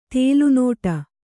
♪ tēlu nōṭa